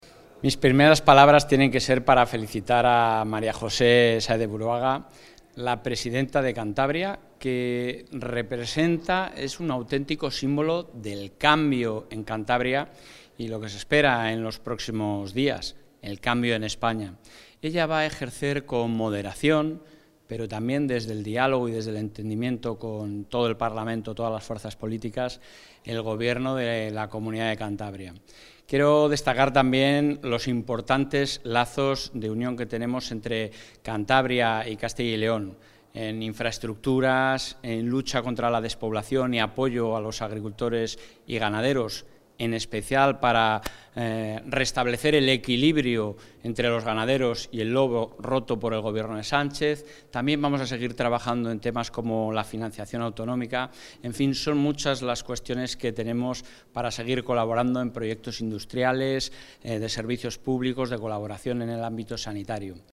Declaraciones del presidente de la Junta.
El presidente de la Junta de Castilla y León, Alfonso Fernández Mañueco, ha asistido hoy, en Santander, a la toma de posesión de María José Sáenz de Buruaga Gómez como presidenta de Cantabria.